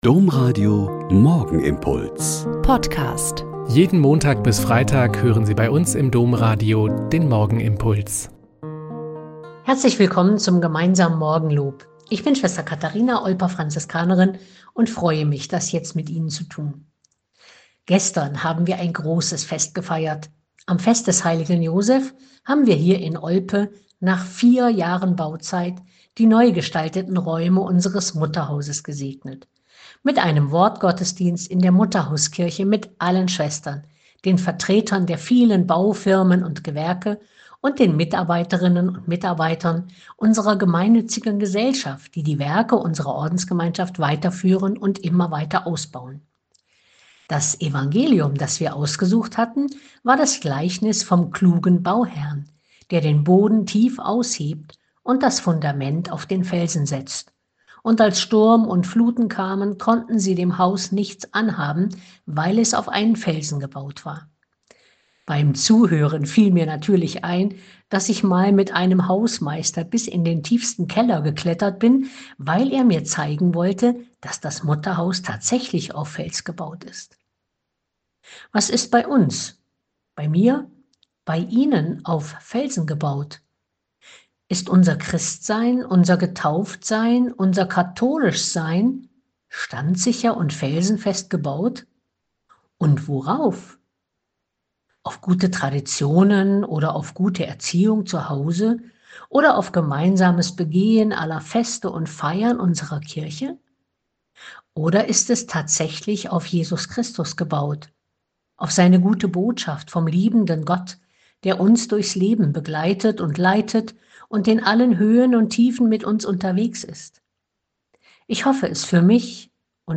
Joh 19,16-30 - Gespräch mit Abt Nikodemus Schnabel OSB - 18.04.2025